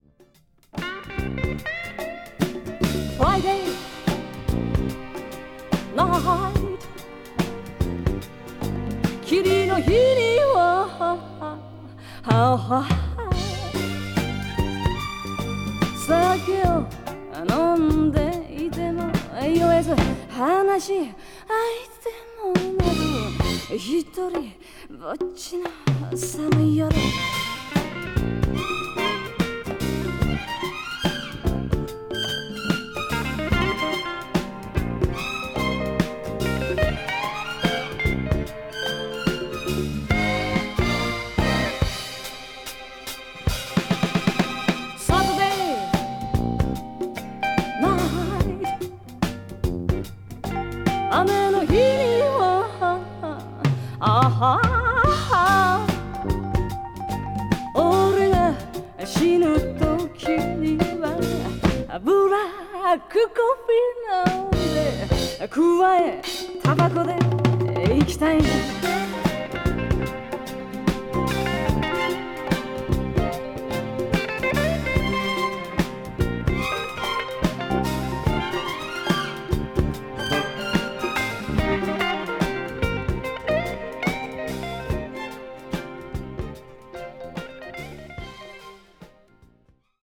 a hidden rock-groove gem